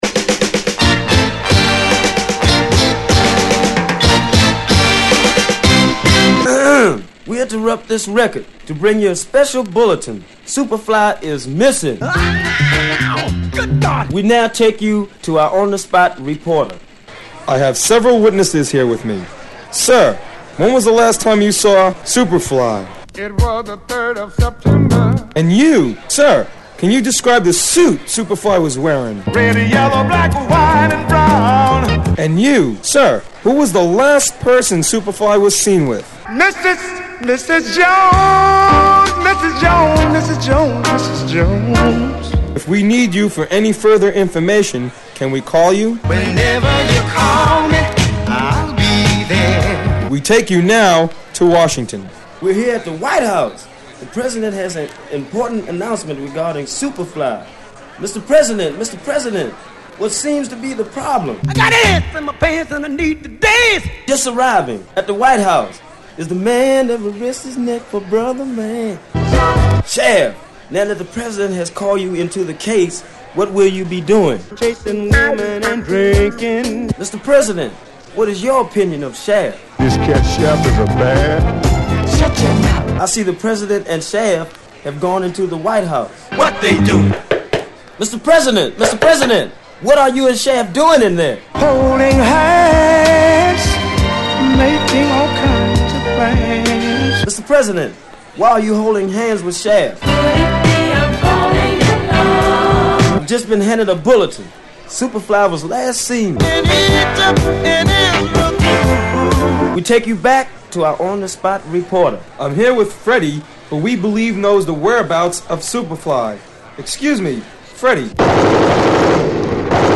Soul~Funk名曲をコラージュした元祖Megamix的な1枚！